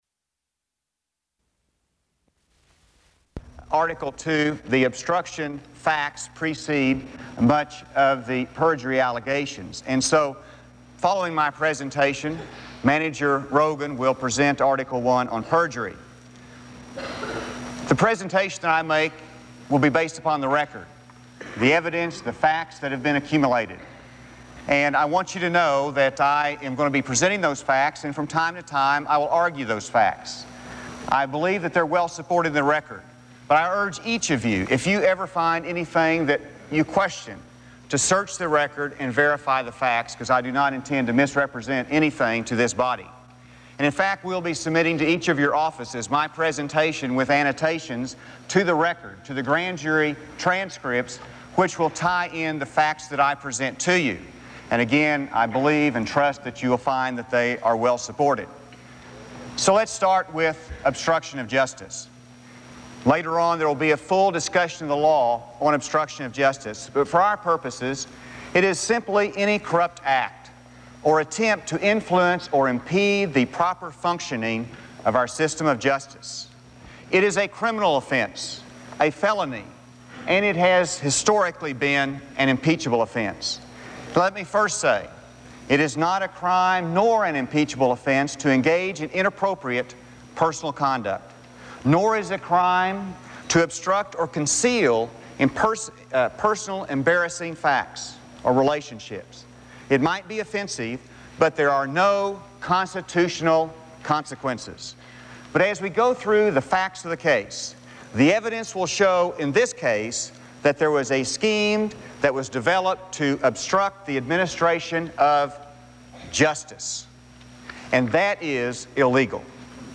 Asa Hutchinson (R-AR) testifies in the impeachment of President Clinton